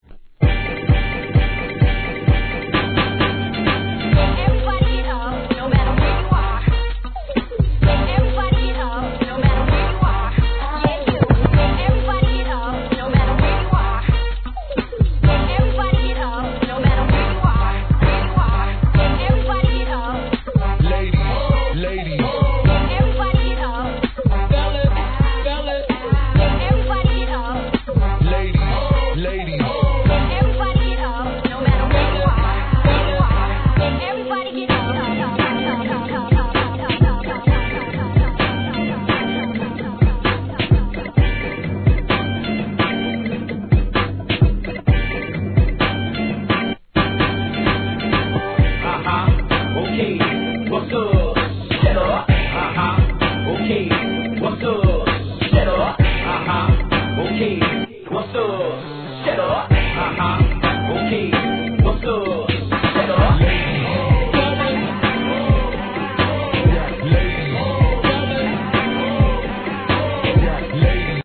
HIP HOP/R&B
中盤よりダウナー展開を見せる技ありなGAL PUSHチュ〜ン!!